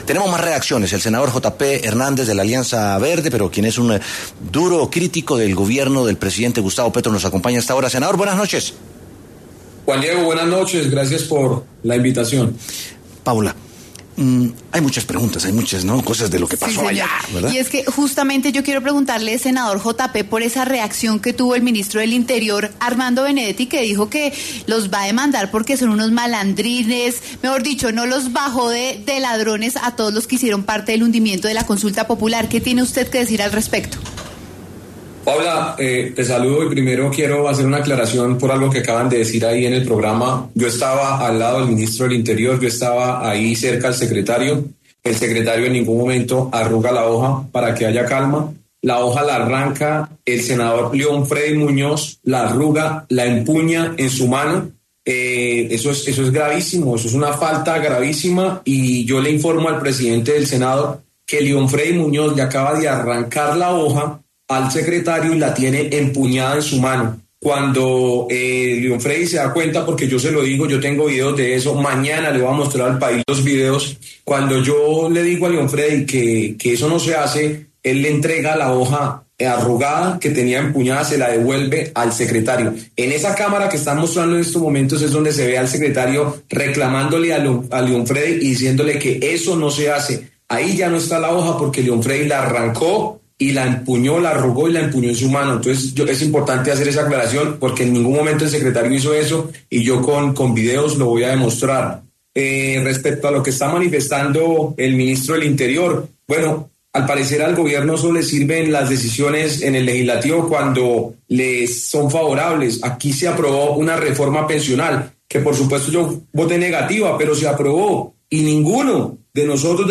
A propósito de esto, el senador de la Alianza Verde Jota Pe Hernández pasó por los micrófonos de W Sin Carreta para hablar sobre el tenso ambiente que se vivió en el Senado tras la votación.